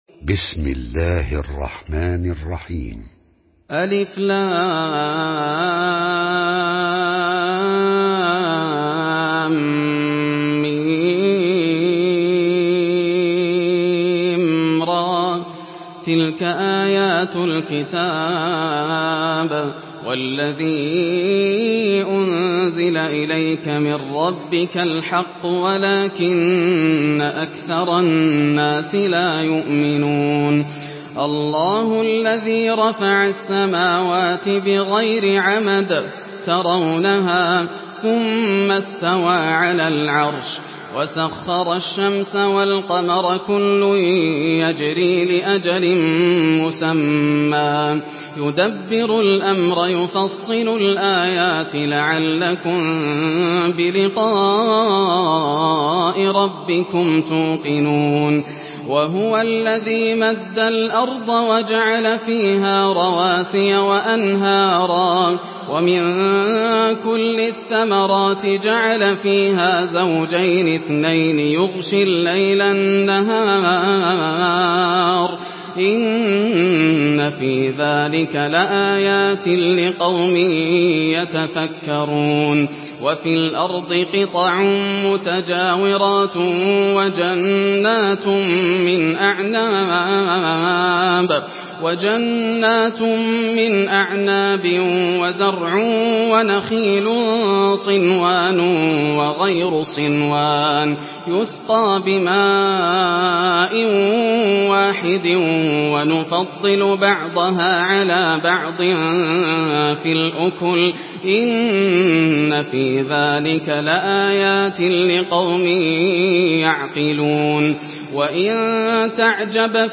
سورة الرعد MP3 بصوت ياسر الدوسري برواية حفص
مرتل حفص عن عاصم